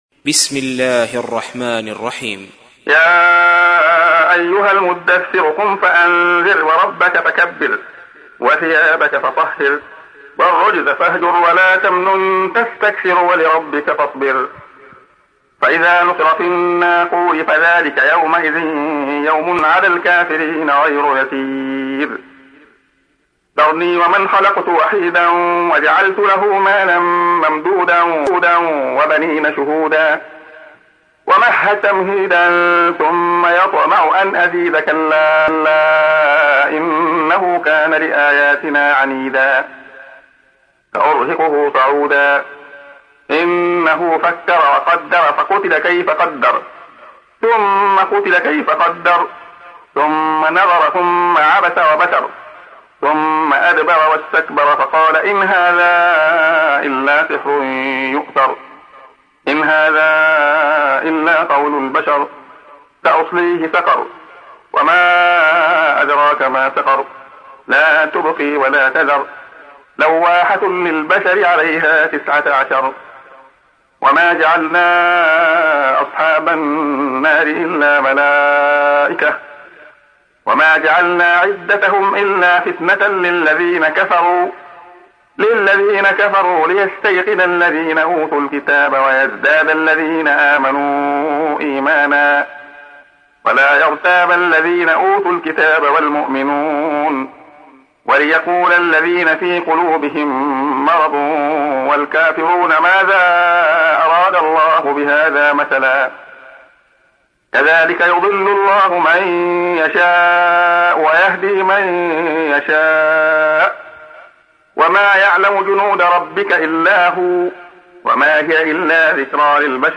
تحميل : 74. سورة المدثر / القارئ عبد الله خياط / القرآن الكريم / موقع يا حسين